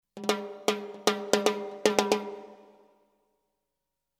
Timbales fills in 115 bpm
The timbales are with light reverb and they are in 115 bpm.
This package contains real timbales fills playing a variety of fills in 115 bpm.
The timbales were recorded using “ AKG C-12” mic. The timbales were recorded mono but the files are stereo for faster workflow. The reverb is stereo on the mono timbales.